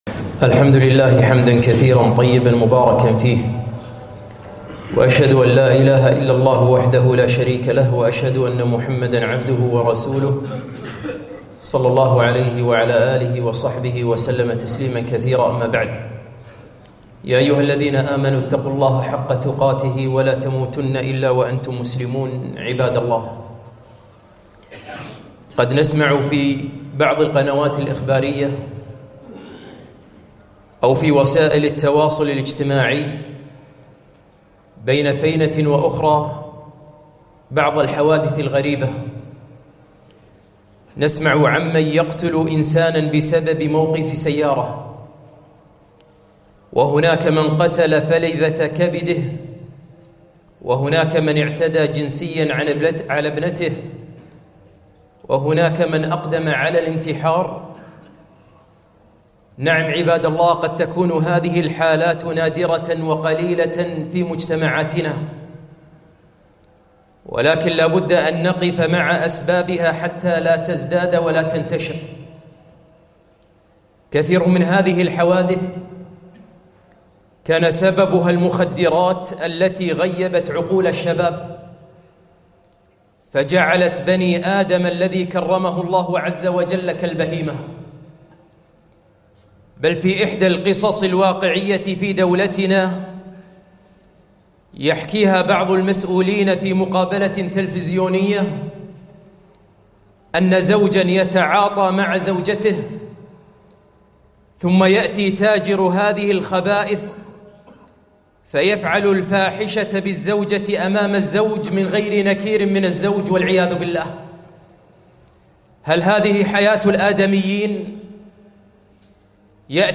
خطبة - آفــة المخــدرات